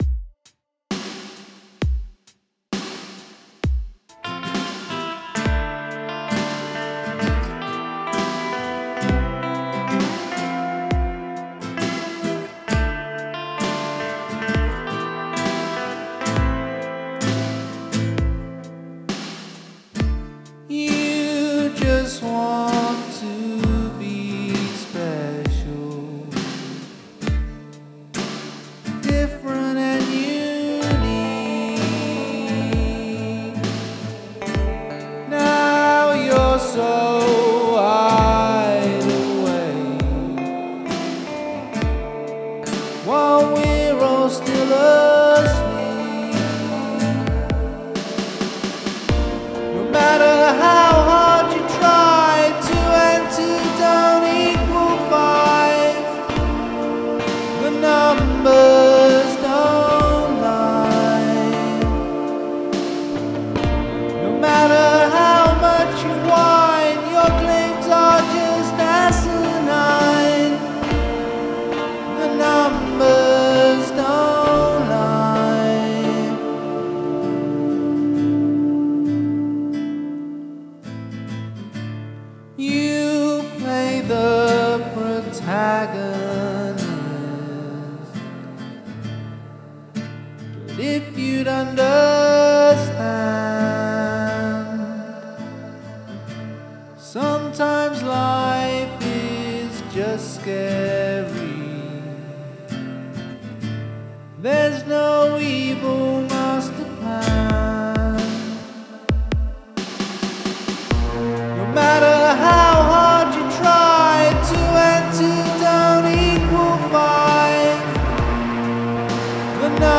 Use the Royal Road chord progression